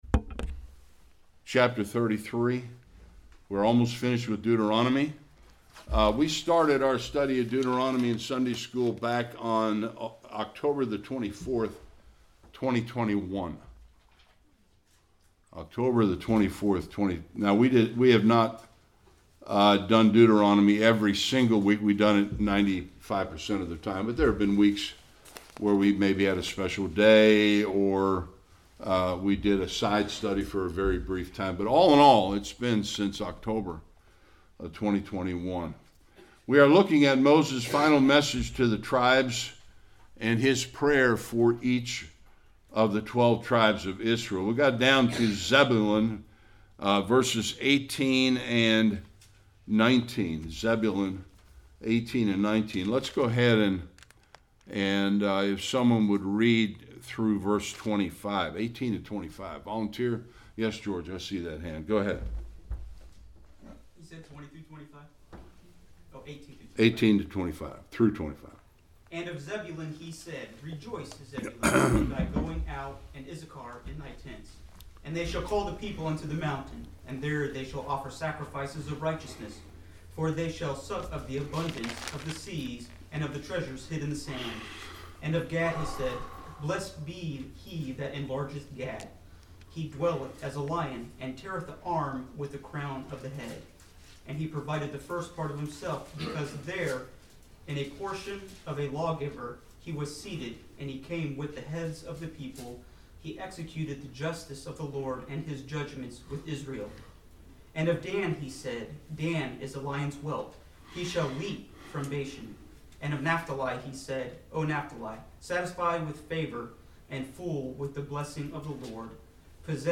18-29 Service Type: Sunday School The farewell address and prayer of Moses for the 12 tribes of Israel